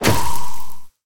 Minecraft Version Minecraft Version 25w18a Latest Release | Latest Snapshot 25w18a / assets / minecraft / sounds / mob / warden / attack_impact_2.ogg Compare With Compare With Latest Release | Latest Snapshot
attack_impact_2.ogg